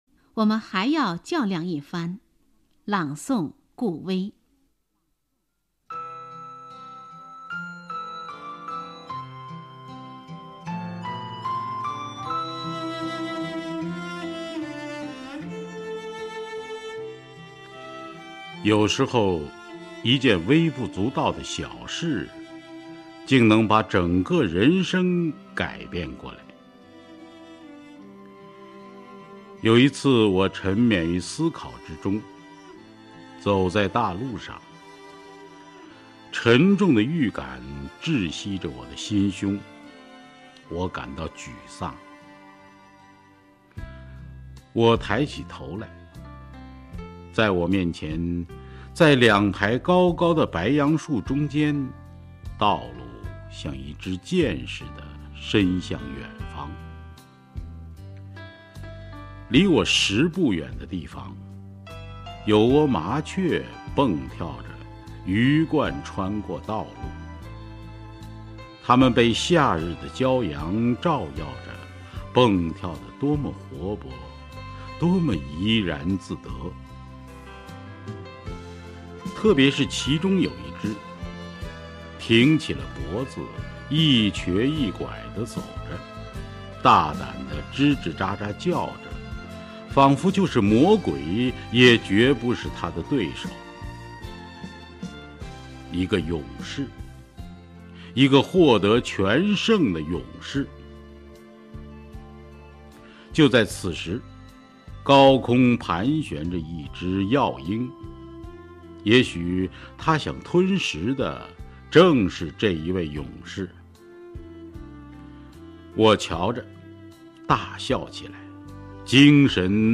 首页 视听 名家朗诵欣赏 顾威
顾威朗诵：《我们还要较量一番》(（俄）伊凡·谢尔盖耶维奇·屠格涅夫)